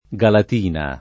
Galatina [ g alat & na ]